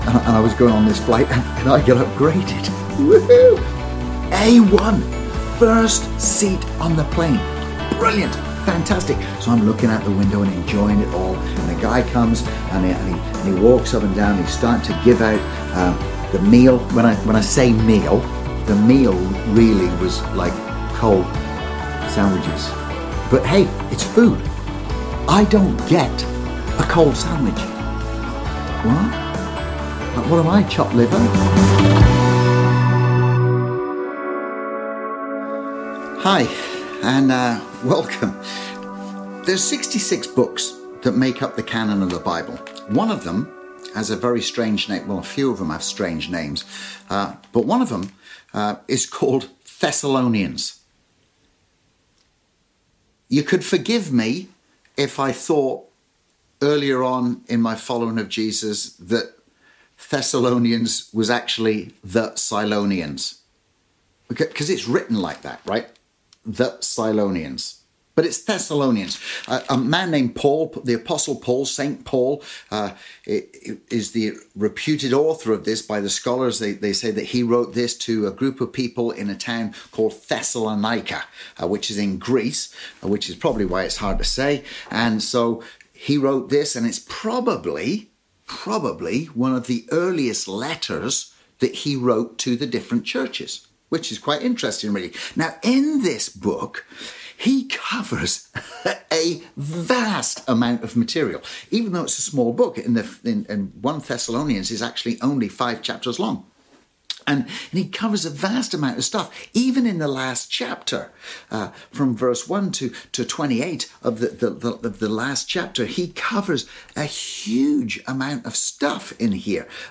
Sermons | Pemberton Community Church